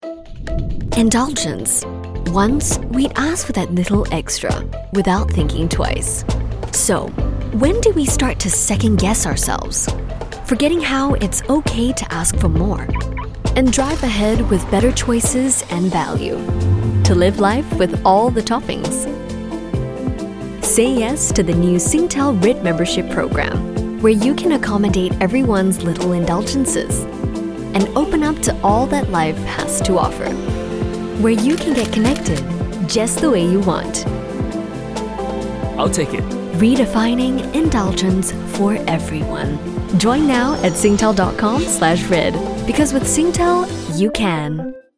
Voice Sample: Singtel Red Membership
EN Asian EN SG
We use Neumann microphones, Apogee preamps and ProTools HD digital audio workstations for a warm, clean signal path.